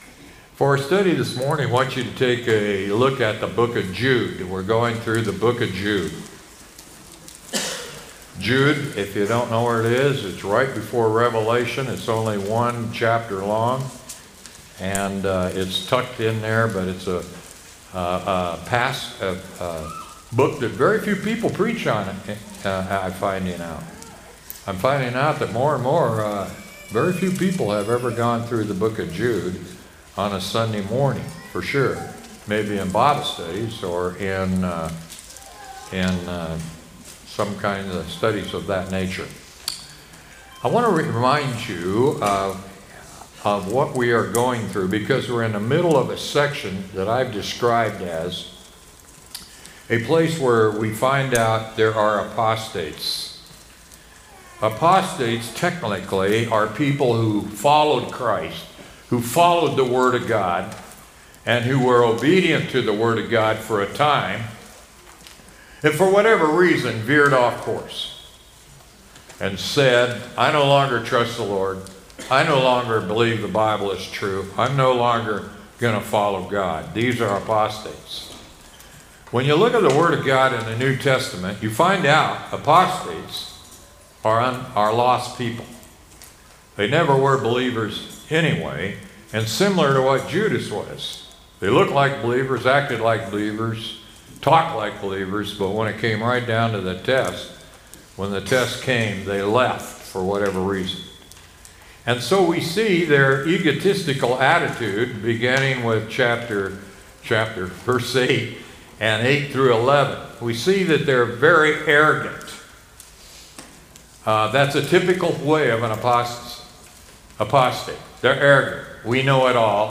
sermon-9-1-24.mp3